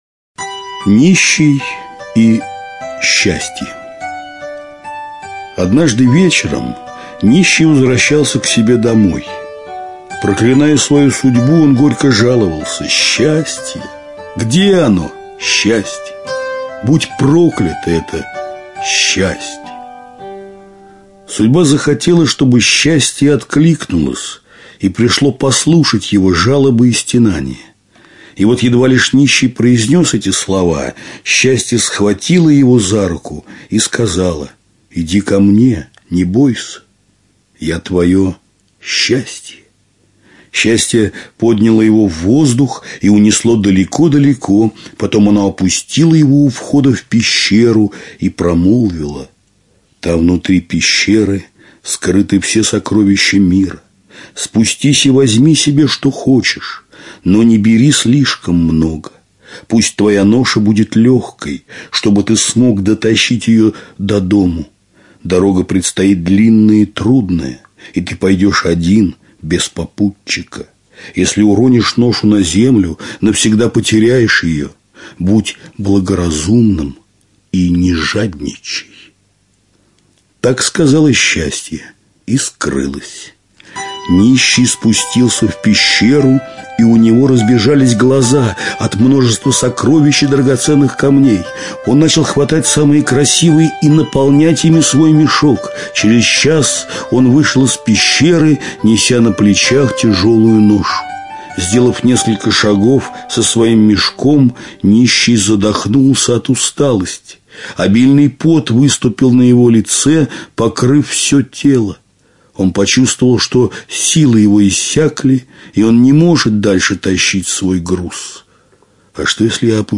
Нищий и счастье - арабская аудиосказка - слушать онлайн